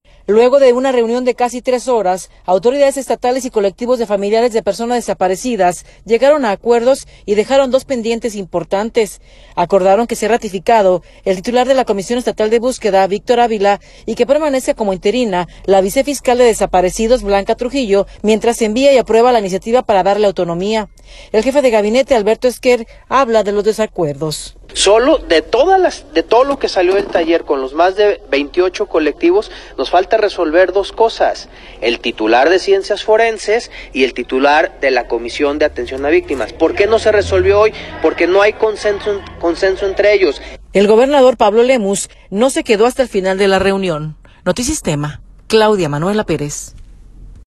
El jefe de gabinete, Alberto Esquer, habla de los desacuerdos.